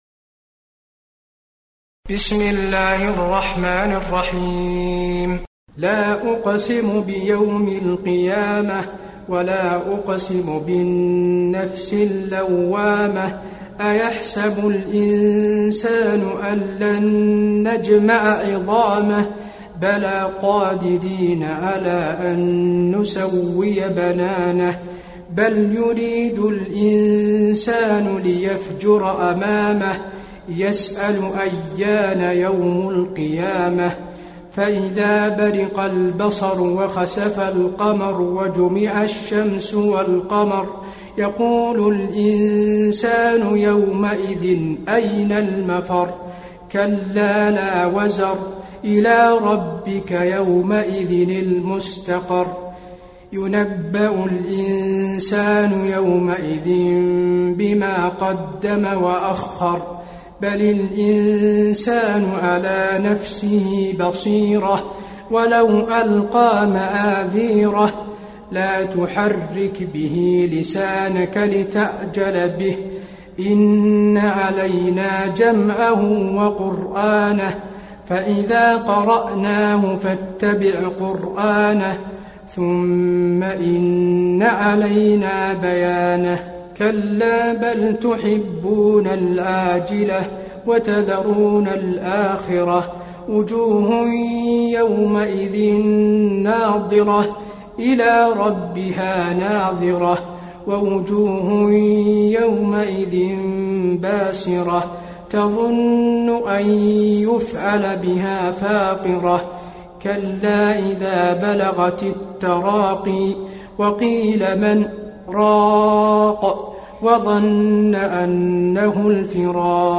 المكان: المسجد النبوي القيامة